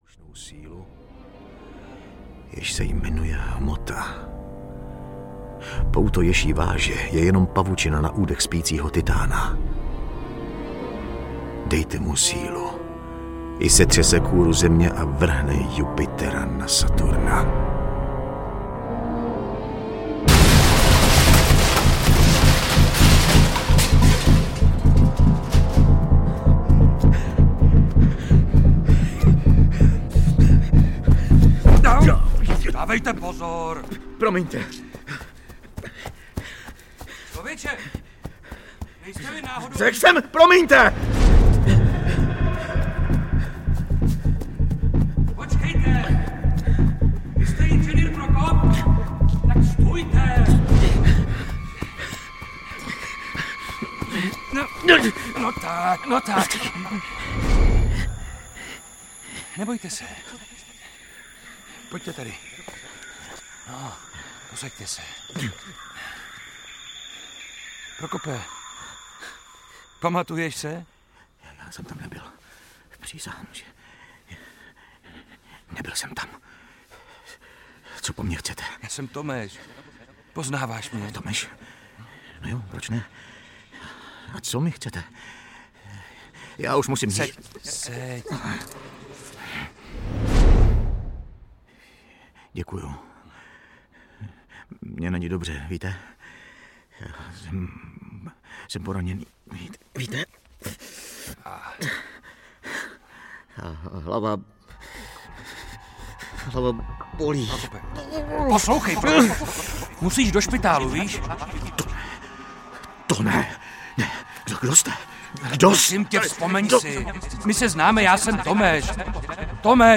Krakatit audiokniha
Ukázka z knihy